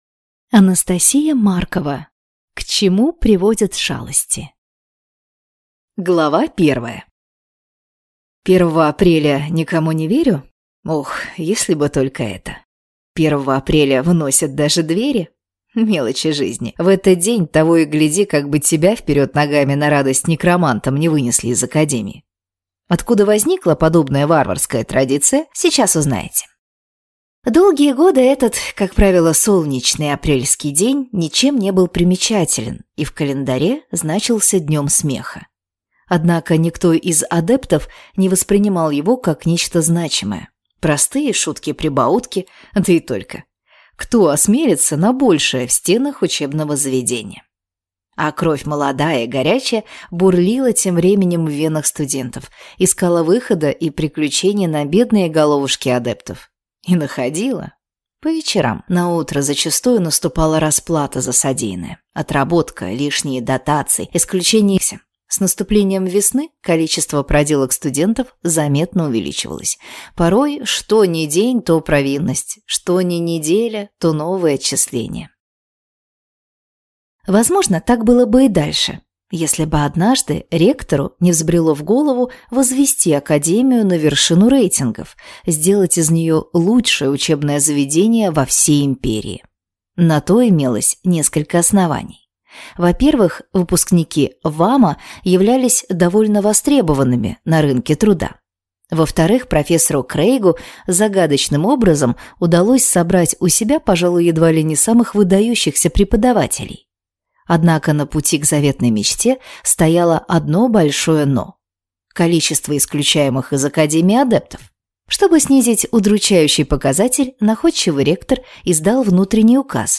Аудиокнига К чему приводят шалости | Библиотека аудиокниг